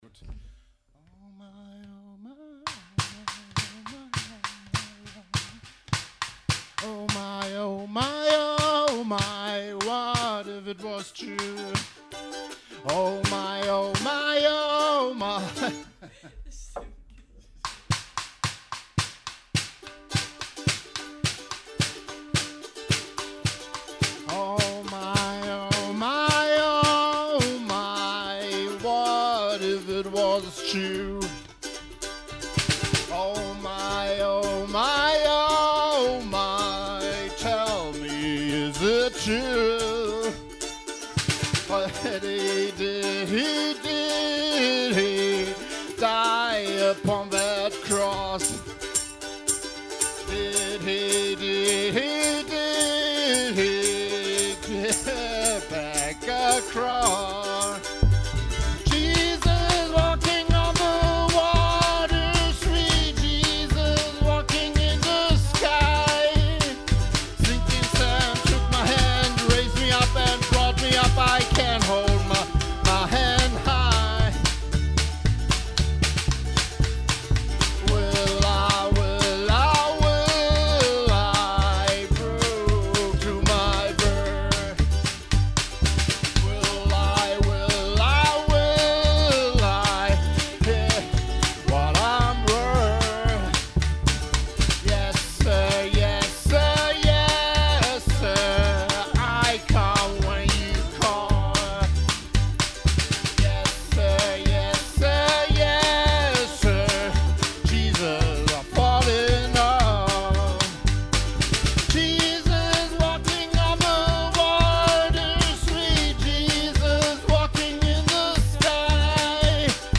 Genre: Folk.